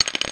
bullet casing sound
shell.ogg